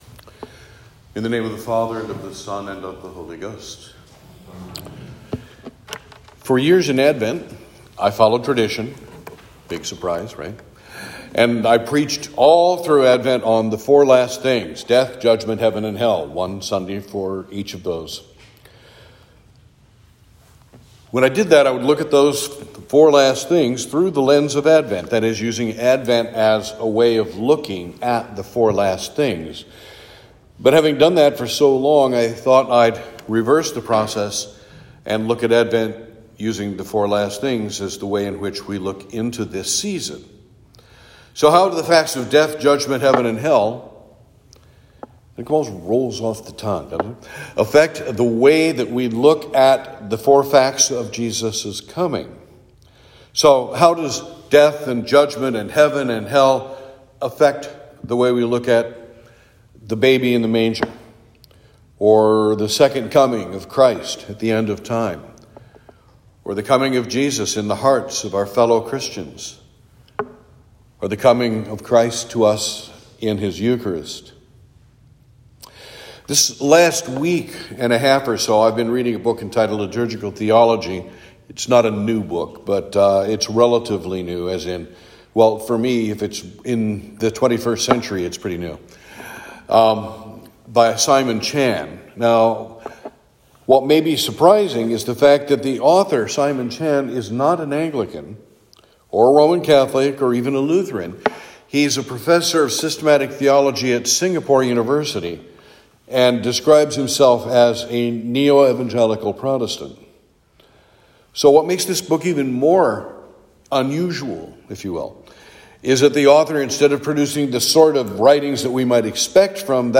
Saint George Sermons Sermon for Advent 2